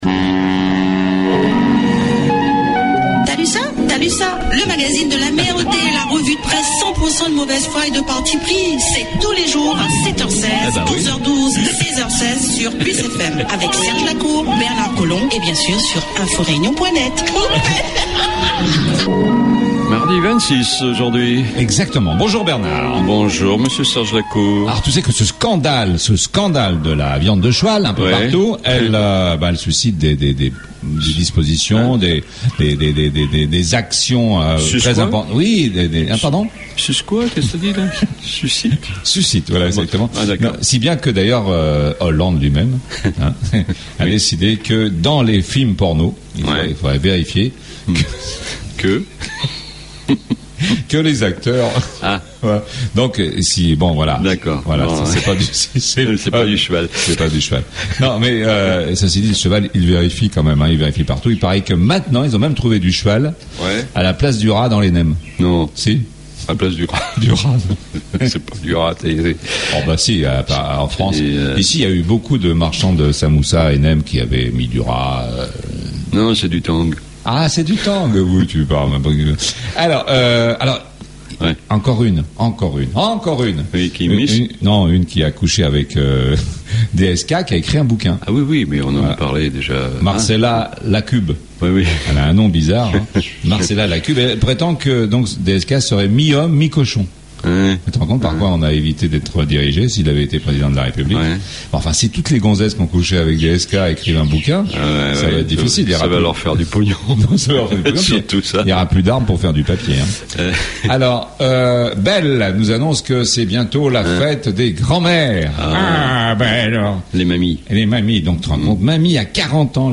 THALUSSA du mardi 26 février 2013 . REVUE DE PRESSE